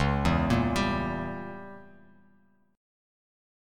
C#dim7 chord